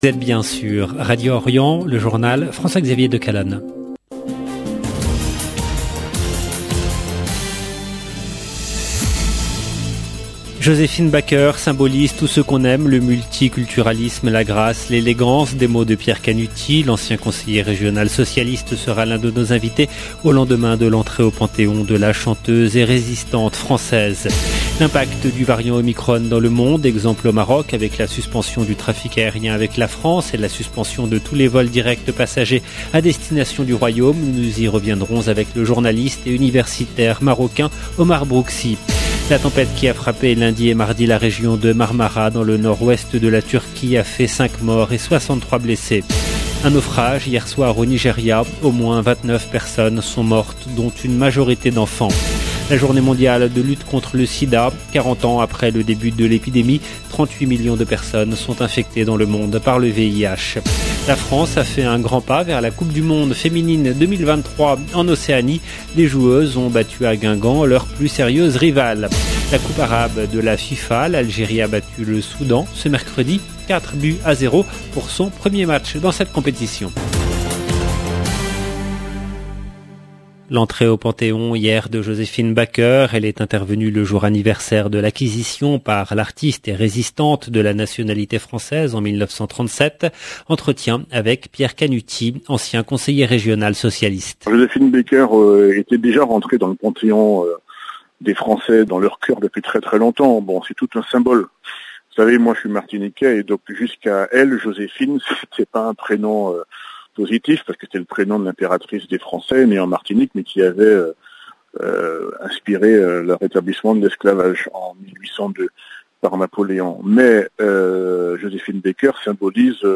LE JOURNAL EN LANGUE FRANCAISE DU SOIR DU 1/12/21